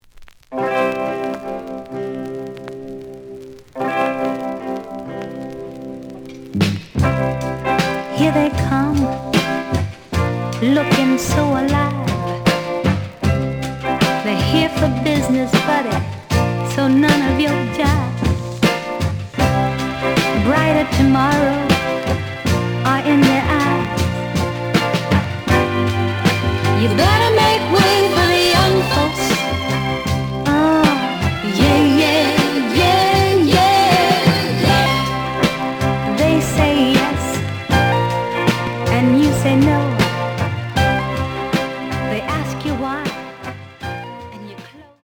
The audio sample is recorded from the actual item.
●Genre: Soul, 60's Soul
Red vinyl.)